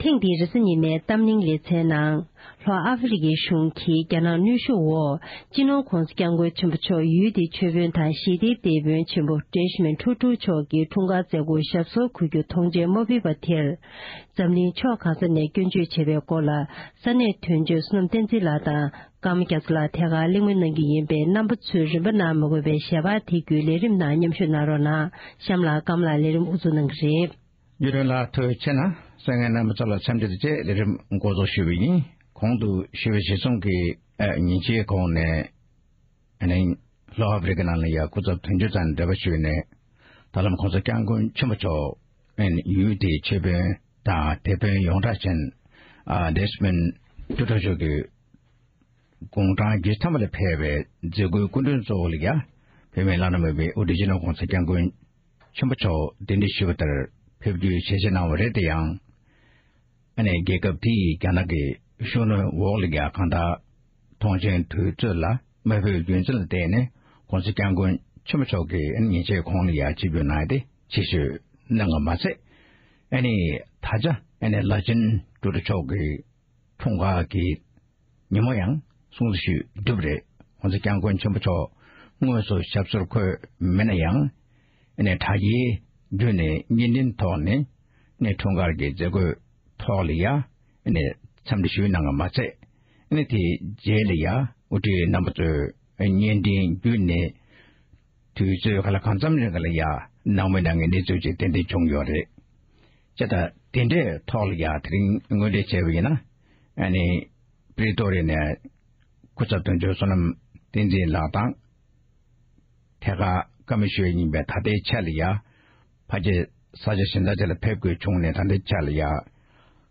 དབར་གླེང་མོལ་གནང་བར་གསན་རོགས༎